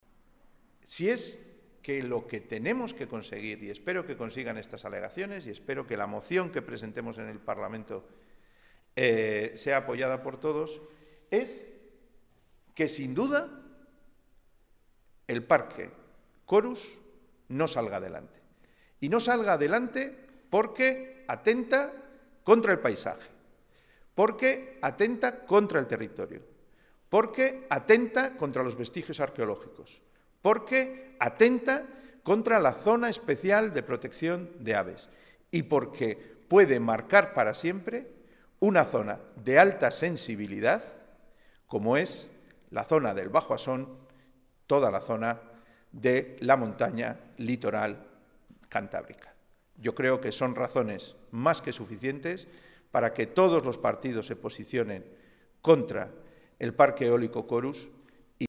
Ver declaraciones de Pedro Hernando, diputado del Partido Regionalista de Cantabria y portavoz del PRC.
Audio Pedro Hernando